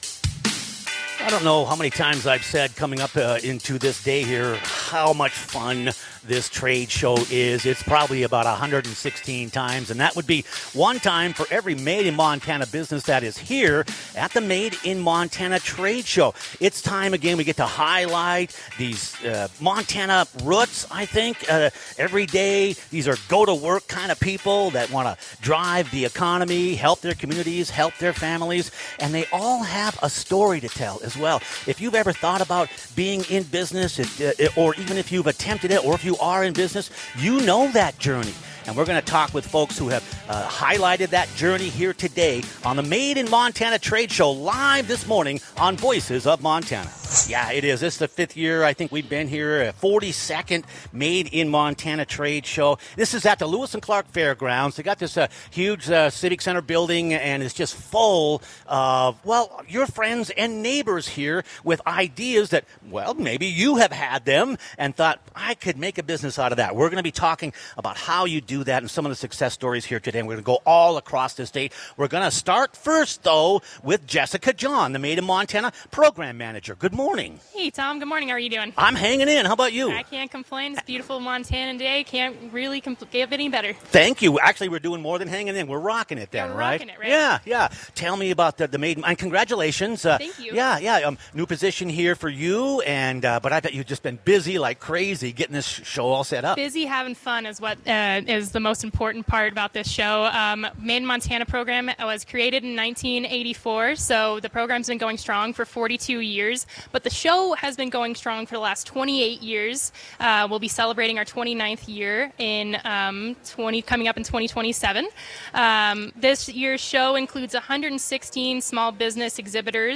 Broadcasting Live from the Made in Montana Trade Show - Voices of Montana